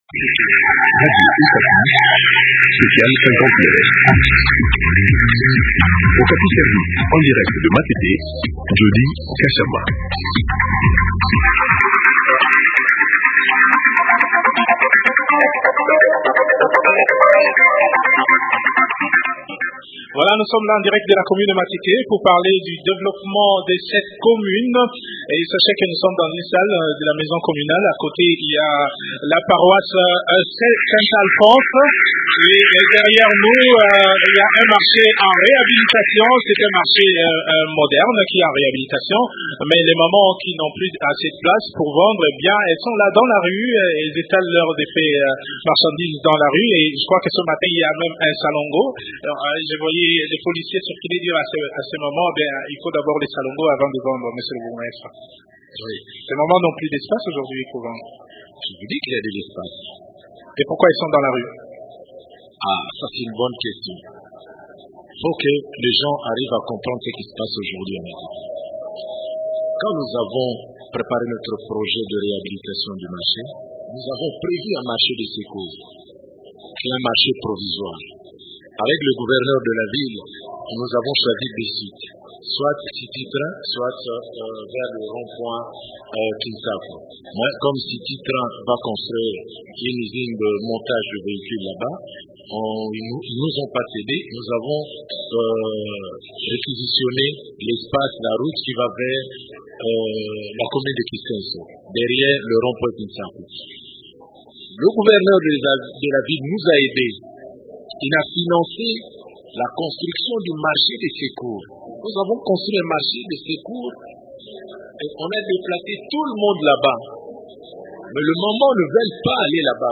Comment pérenniser ces actions de développement durable dans la commune de Matete ? Tous les invités sont unanimes : il faut associer la population à la gestion de ce patrimoine.